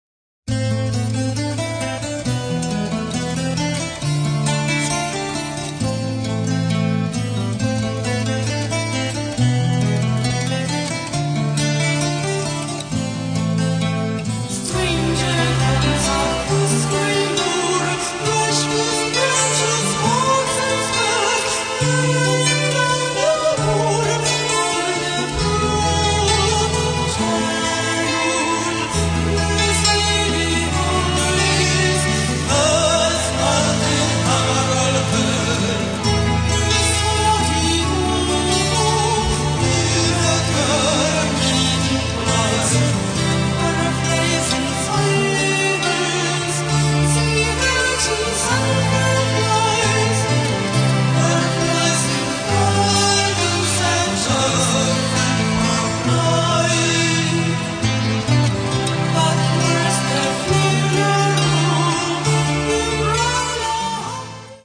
イタリアのベテランゴシックフォークバンド初期作品リマスター版
acoustic guitar, electric guitar, drum machine
keyboards, piano, pipe-organ